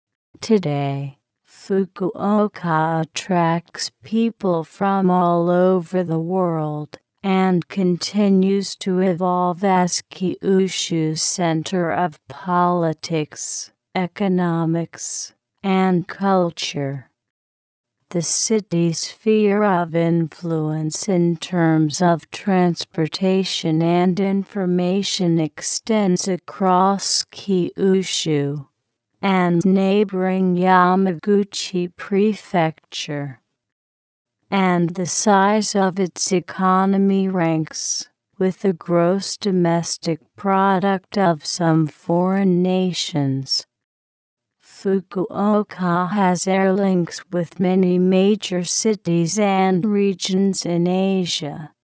注：上表の音声データはTextAloudによるコンピュータ合成音です。